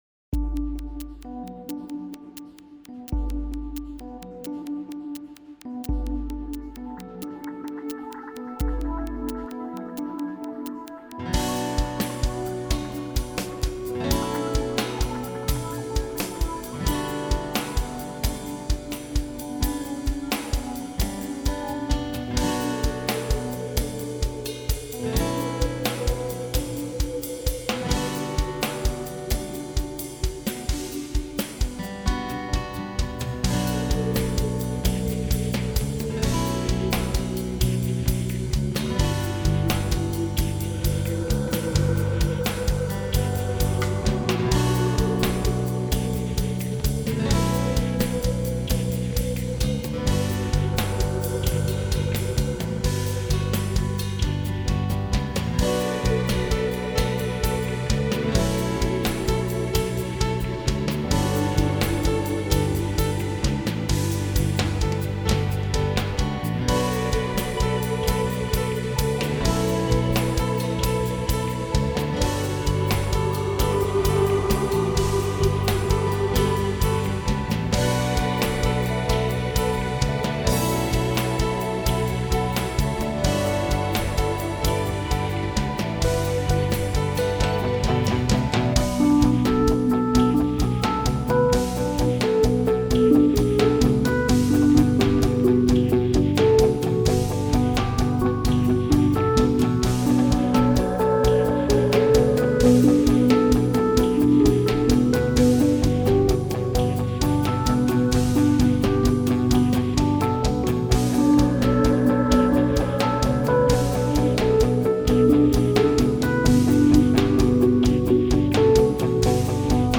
Compos instrumentales un peu prog
piano, synthés, basse, guitares
batterie